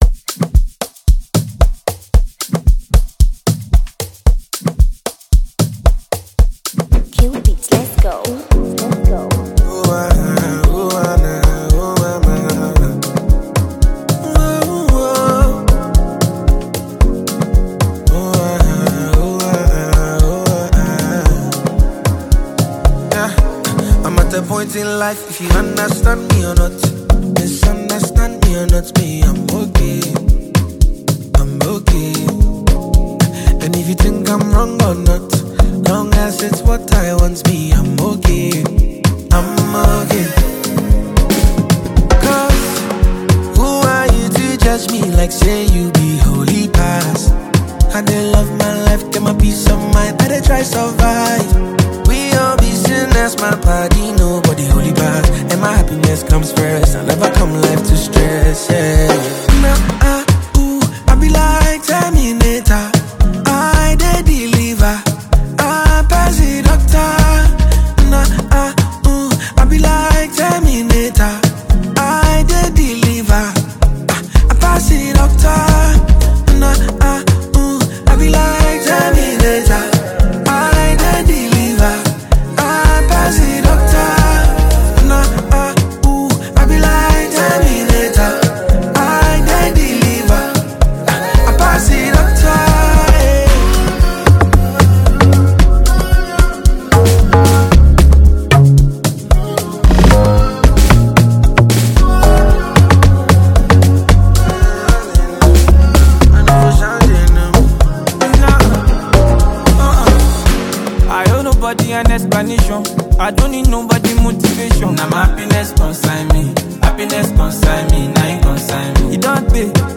Amapiano
which has a blend of Afro-Pop.